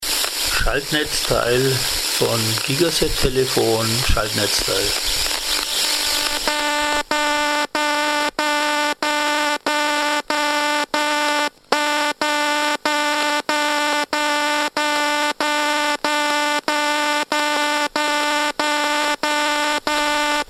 STÖRQUELLEN AUDIODATENBANK
Schaltnetzteil / Ladegerät Siemens Gigaset S2582 DC 4V Low E-Field Akku stand-by 100-149